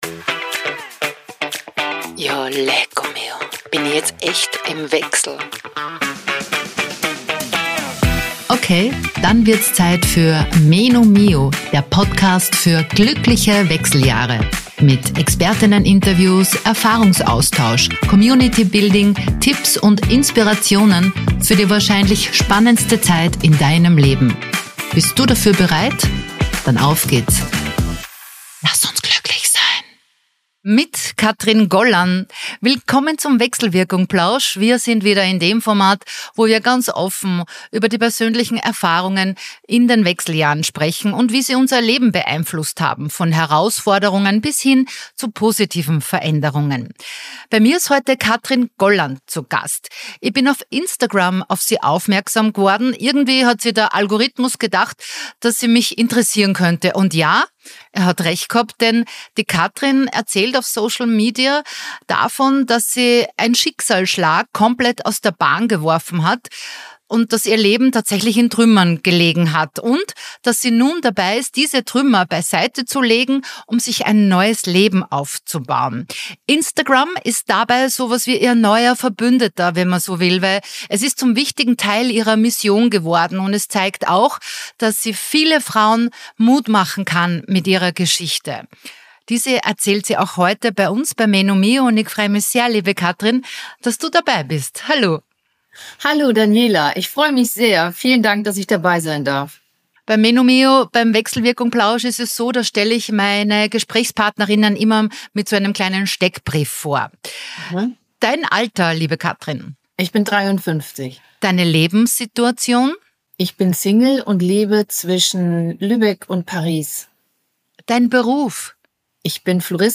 In dieser Episode geht es um Wechseljahre, mentale Gesundheit, Alleinsein lernen, Selbstfürsorge, neue Lebenswege ab 40 und darum, wie kleine Schritte große Veränderungen möglich machen. Ein ehrliches Gespräch über Verlust, innere Stabilität und die Frage, wie Frauen in den Wechseljahren wieder Vertrauen in sich selbst finden können.